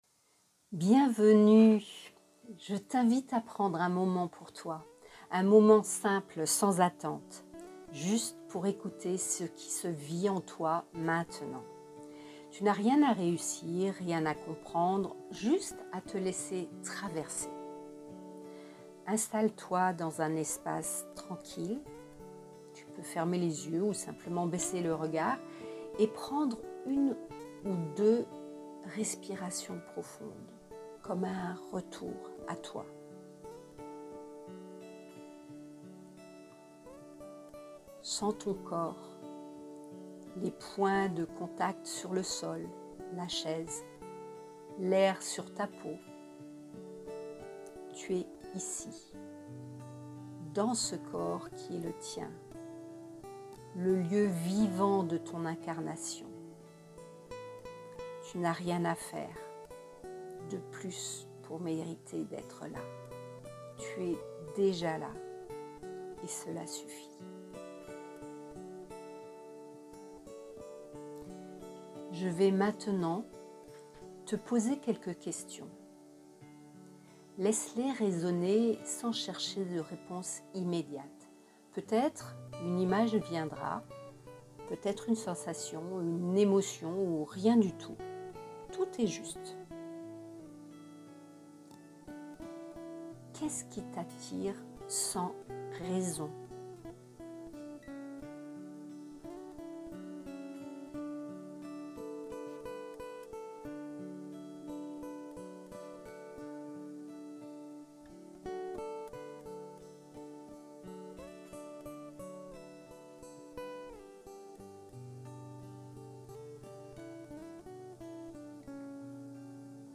et à te laisser guider par ce court audio introspectif.